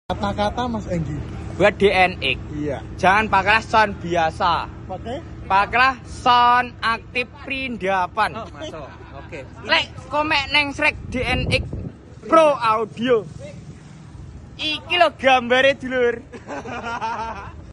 pawai budaya desa ngentrong suport